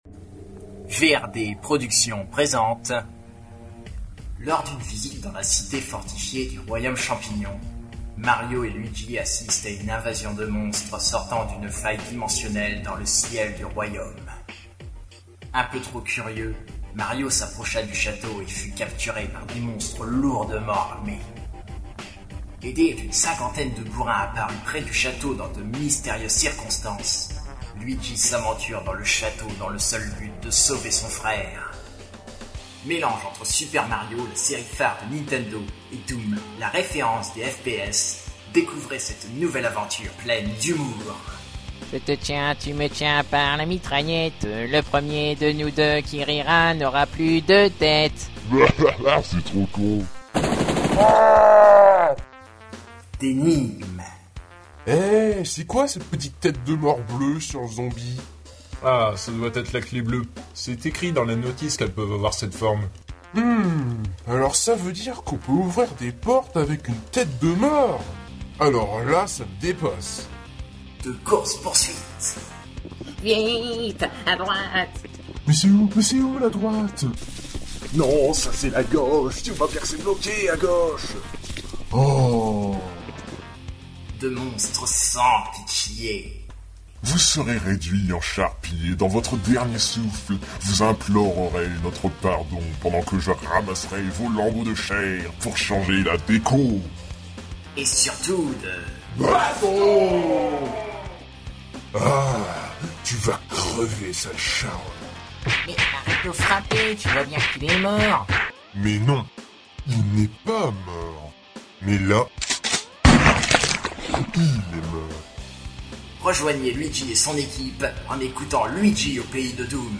Episode 1 (Saison 1) - Bande annonce